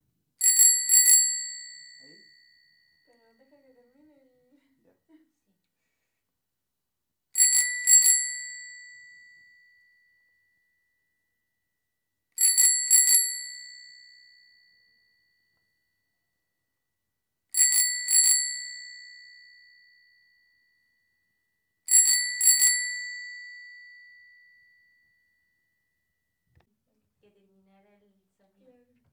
Bike Bell
Bell Bicycle Bike Ding Ring Ting sound effect free sound royalty free Sound Effects